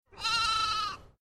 ACTIVITAT 8. QUIN ANIMAL FA AQUEST SOROLL?
ovella.mp3